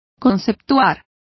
Complete with pronunciation of the translation of deem.